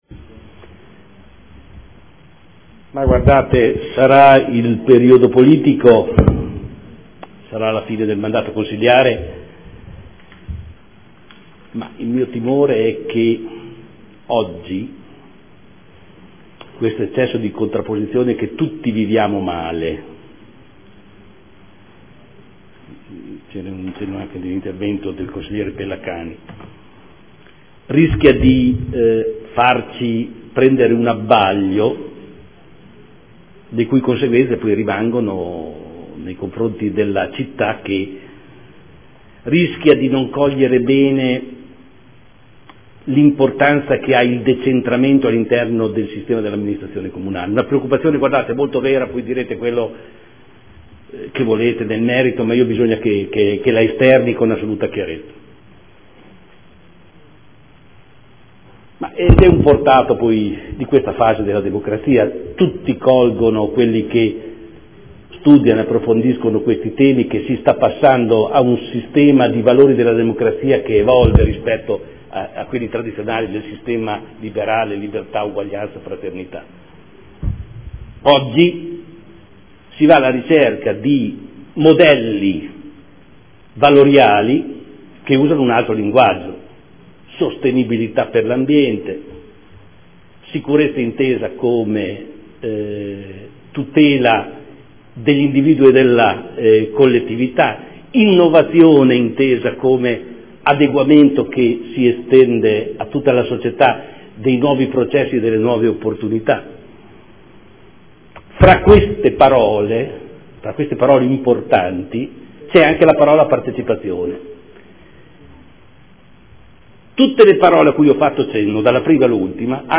Giorgio Pighi — Sito Audio Consiglio Comunale
Seduta del 06/02/2014 - Modifiche allo Statuto comunale – Approvazione